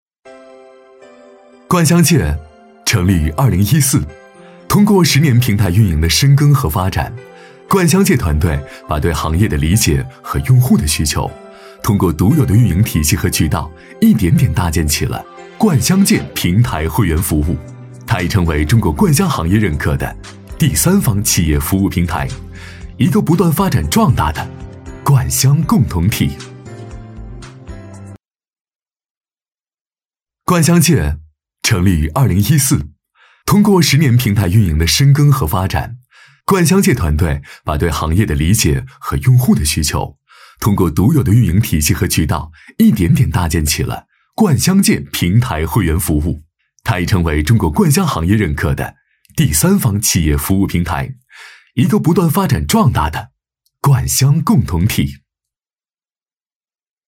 男B34-罐箱界-磁性科技感
男B3-磁性稳重 质感磁性
男B34-罐箱界-磁性科技感.mp3